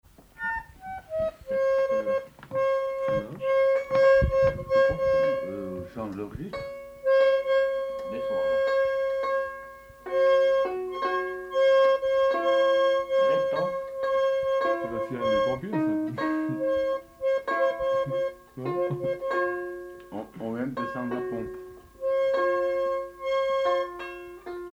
Accordage violon-accordéon